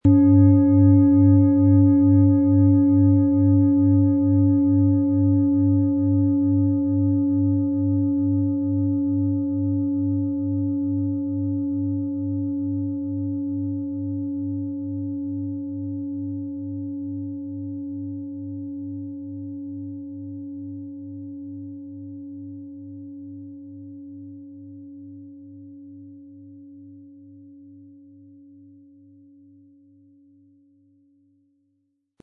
Handgetriebene, tibetanische Planetenklangschale Merkur.
• Tiefster Ton: Uranus
• Höchster Ton: Biorhythmus Körper
Der passende Schlegel ist umsonst dabei, er lässt die Schale voll und harmonisch tönen.
PlanetentöneMerkur & Uranus & Biorhythmus Körper (Höchster Ton)
MaterialBronze